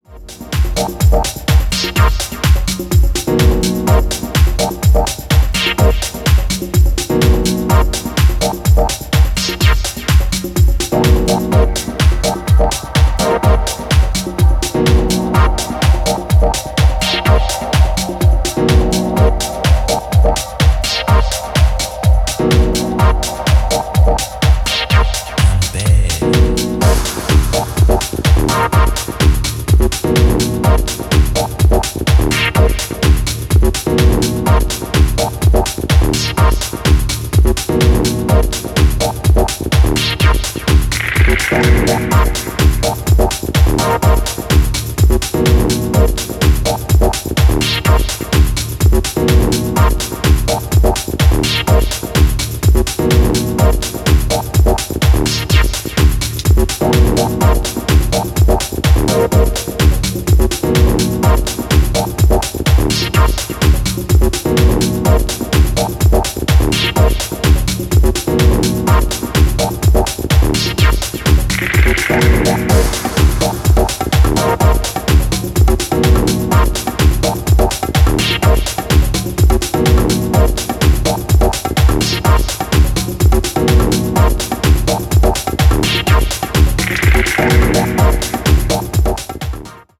太いボトムとパーカッションのグルーヴにシンセリフの残響がこだまする